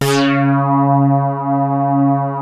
P.5 C#4 1.wav